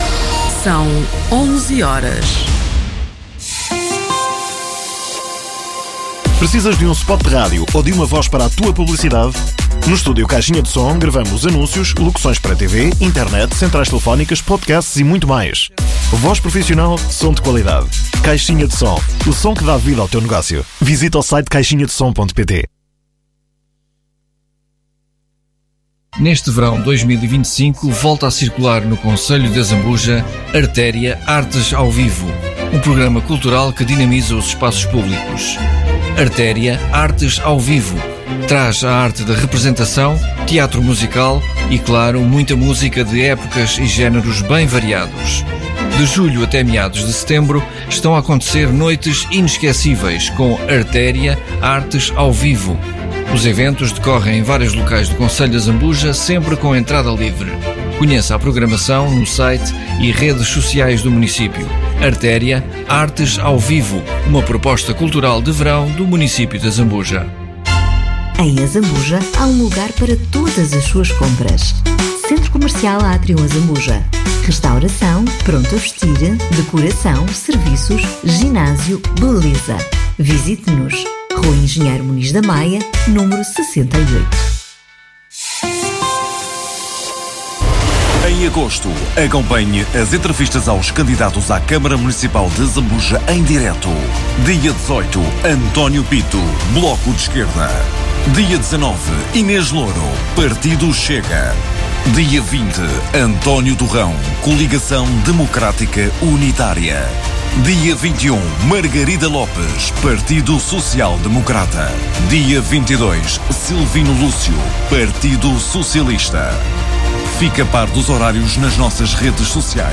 Autárquicas 2025- Azambuja - Entrevista a Silvino Lúcio - PS - Jornal e Rádio Valor Local Regional - Grande Lisboa, Ribatejo e Oeste